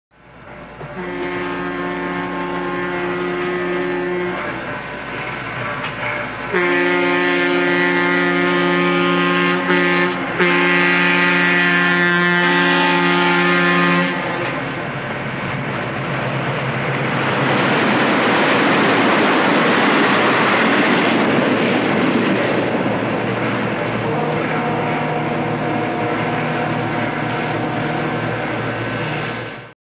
دانلود آهنگ سوت قطار 4 از افکت صوتی حمل و نقل
دانلود صدای سوت قطار 4 از ساعد نیوز با لینک مستقیم و کیفیت بالا
جلوه های صوتی